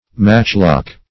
Matchlock \Match"lock`\, n.